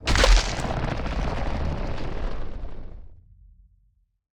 creaking_spawn.ogg